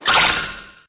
Cri de Meltan dans Pokémon HOME.